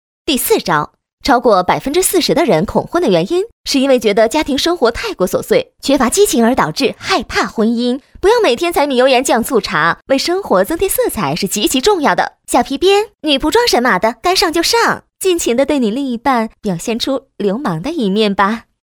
当前位置：首页 > 配音题材 > 病毒配音
女声配音